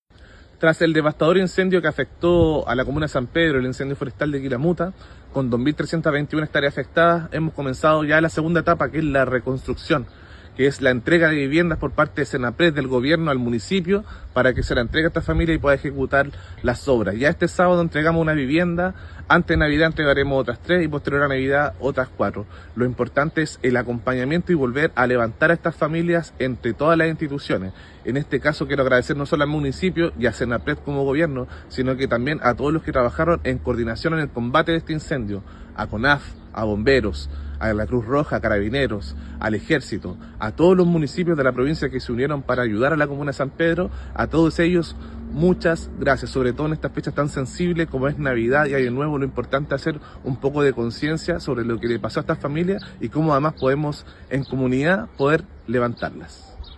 El Delegado Provincial de Melipilla, Bastián Alarcón, informó que ya se está avanzando en el proceso de reconstrucción y entrega de viviendas de emergencia para las familias afectadas tras el incendio  forestal ocurrido en el sector de Quilamuta, comuna de San Pedro, siniestro que dejó 10 viviendas y una bodega afectadas.
AUDIO-viviendas-Delegado-Alarcon.mp3